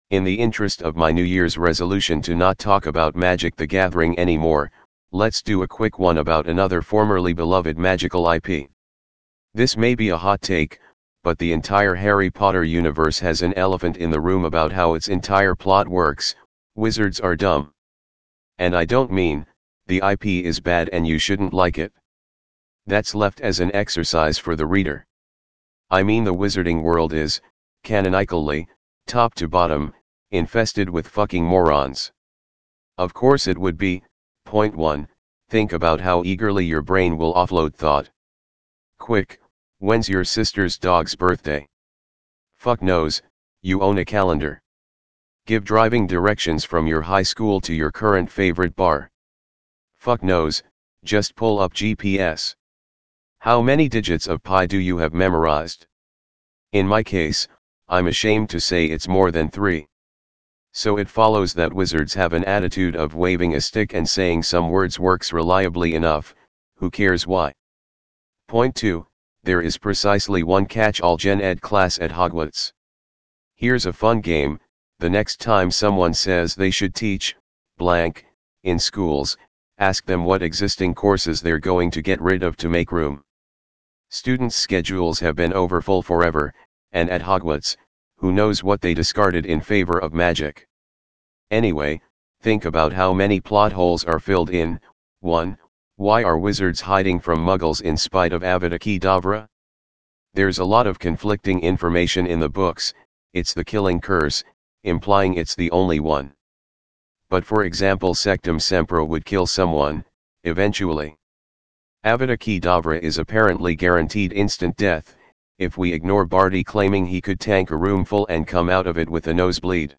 primaryVO.wav